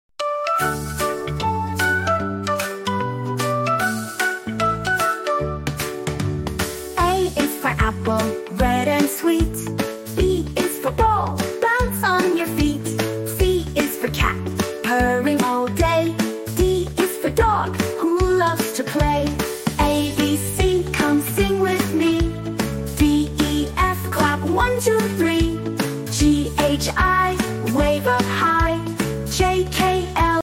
Learn the Alphabet with Music & Rhymes!